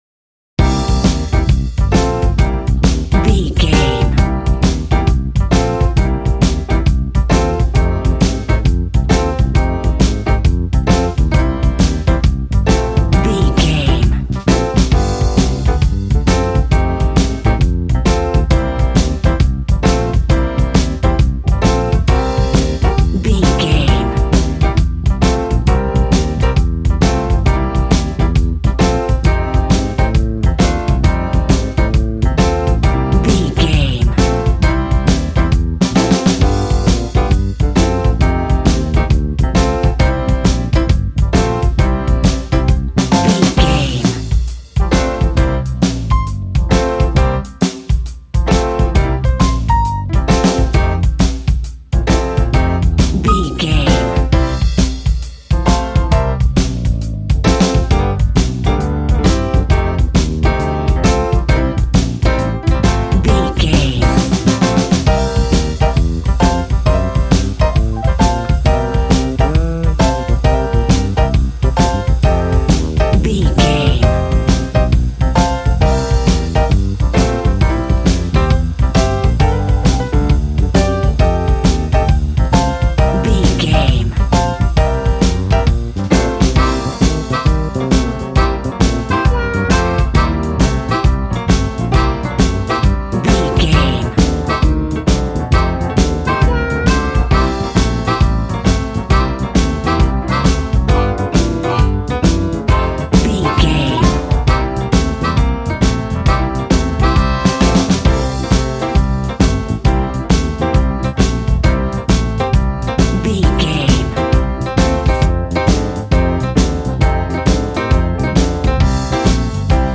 Ionian/Major
sad
mournful
bass guitar
electric guitar
electric organ
drums